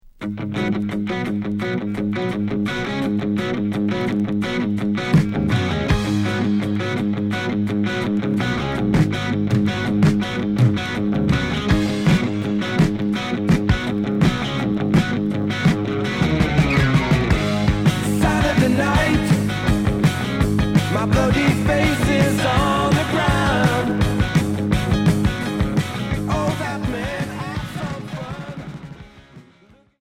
Heavy rock boogie Premier 45t retour à l'accueil